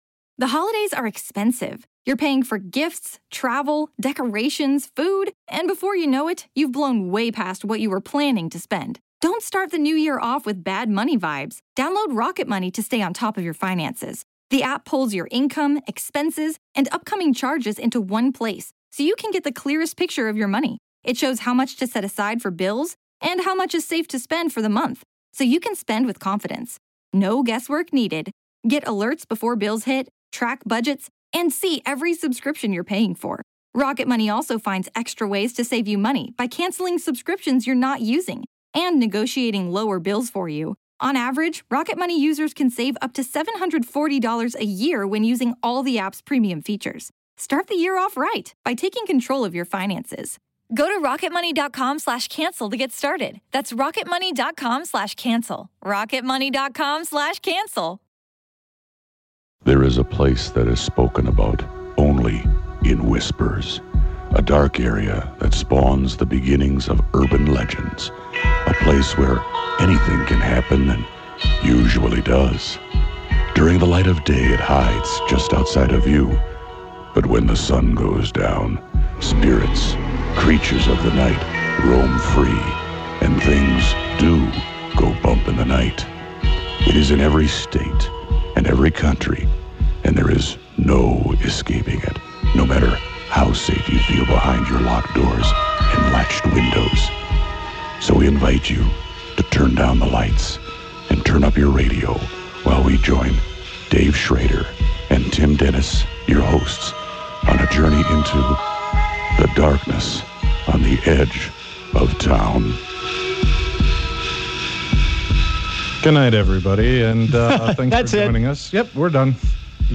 The boys wanted to start the show off with a bang... but they started it off with... salsa music?!